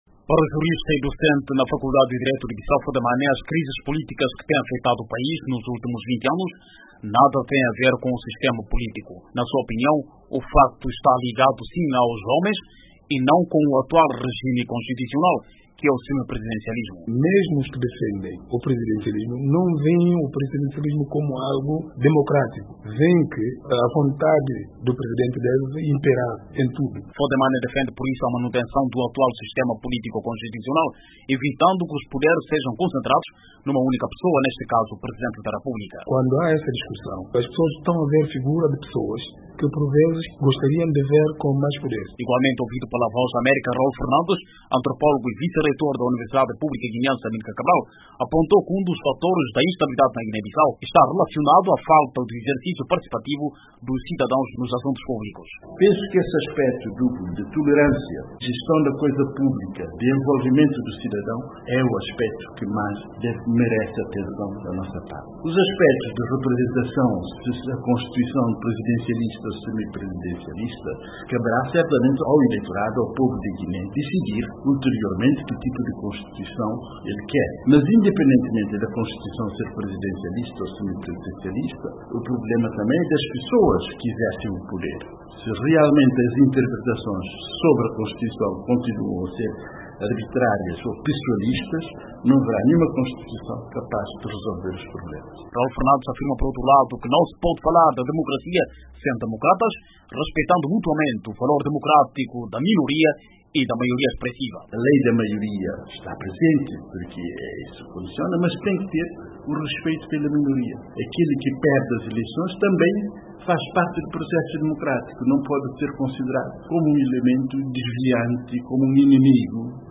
Crises guineenses são provocadas pelos homens e não pelo sistema político, dizem analistas